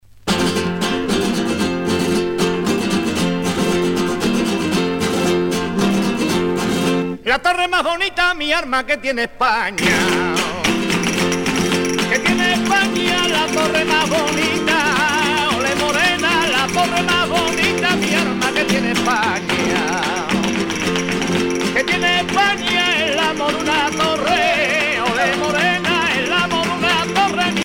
danse : sevillana